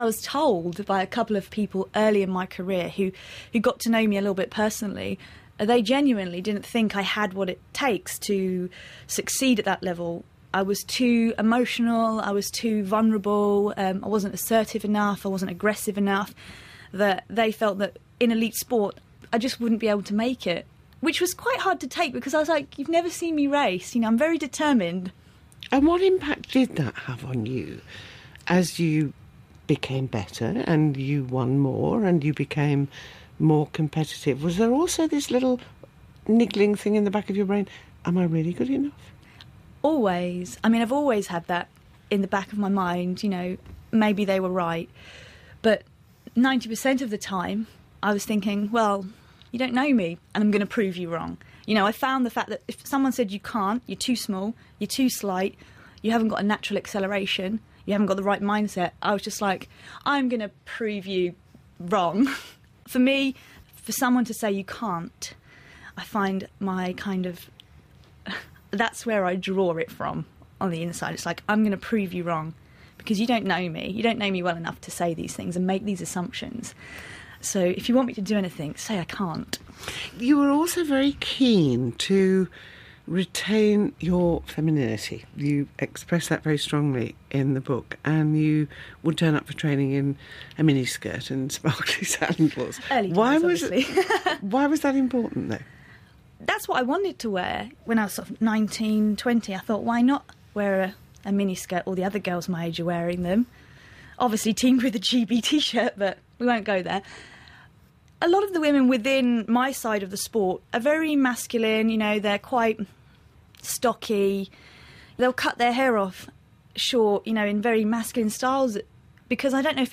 Gold medal winning track cyclist Victoria Pendleton joins Jenni Murray on Woman’s Hour. In this clip, Victoria talks about the early years of her career, why she became determined to prove her detractors wrong, and how she retains her femininity in the male-dominated world of sport.